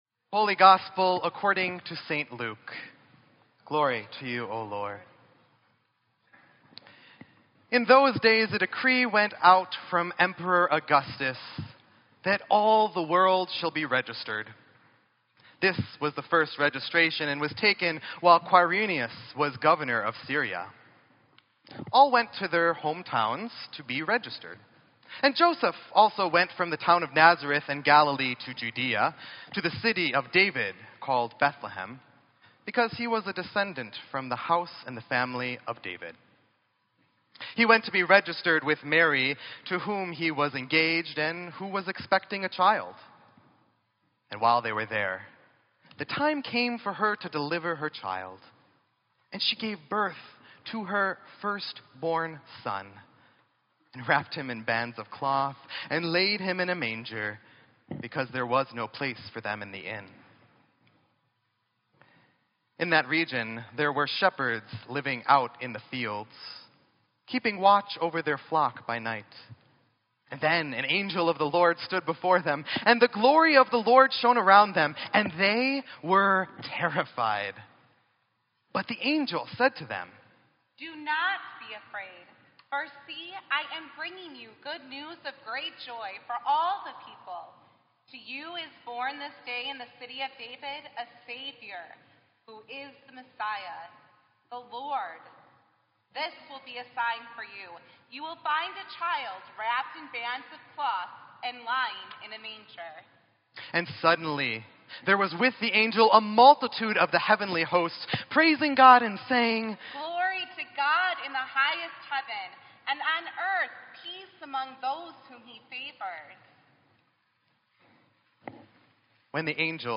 Sermon_12_24_16-2.mp3